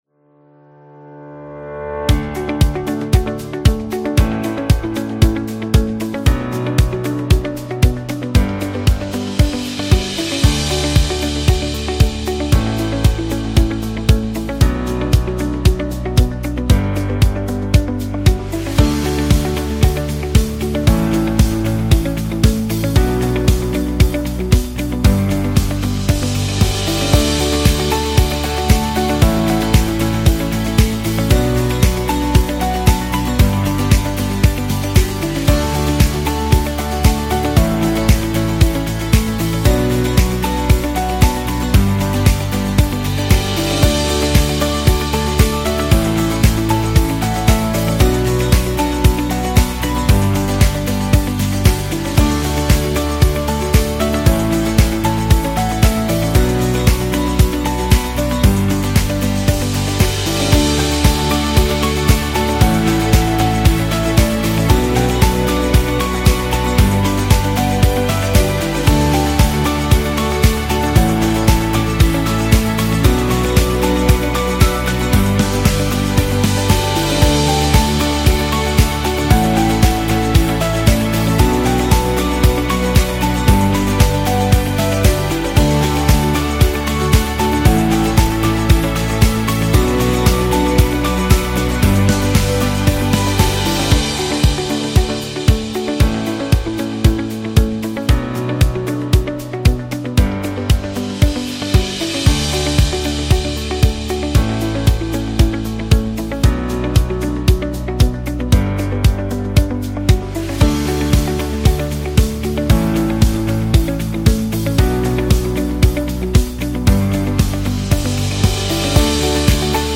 Corporate Presentation